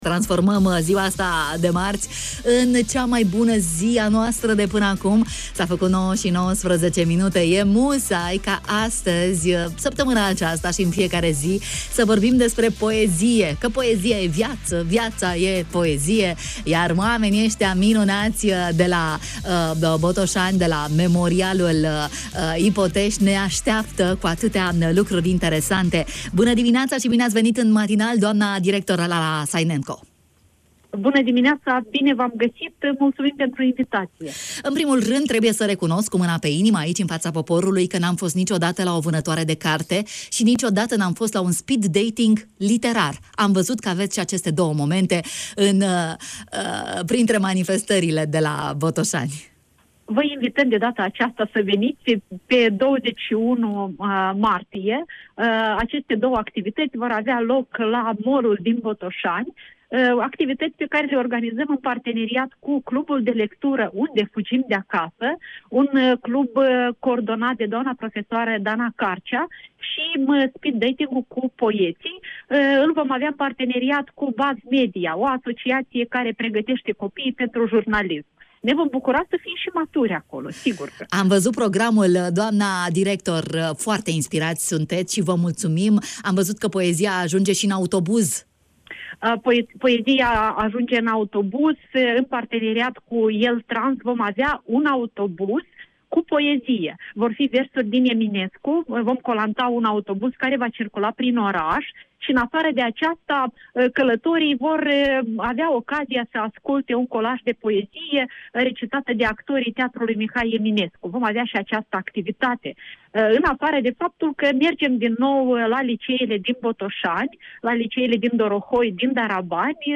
în direct la Bună Dimineața